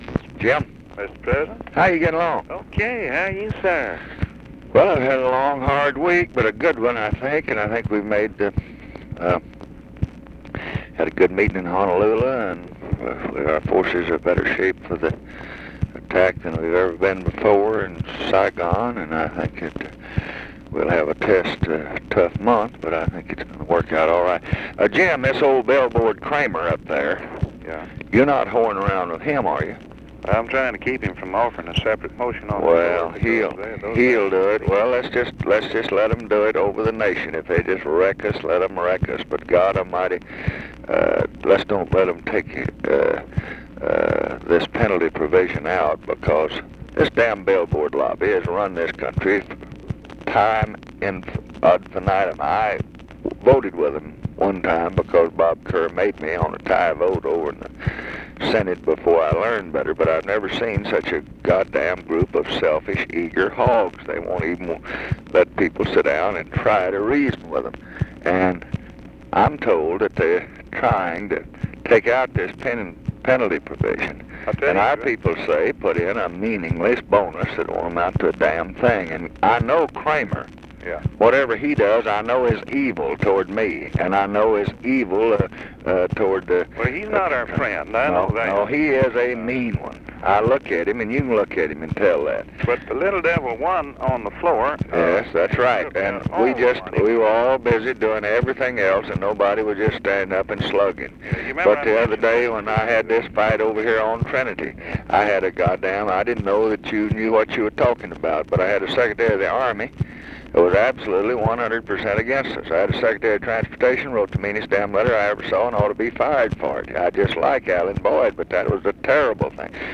Conversation with JIM WRIGHT, July 24, 1968
Secret White House Tapes